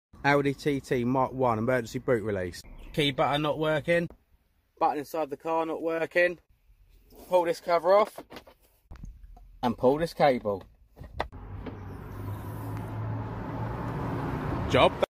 AUDI TT MK1 8N EMERGENCY sound effects free download
AUDI TT MK1 8N EMERGENCY TRUNK BOOT TAILGATE RELEASE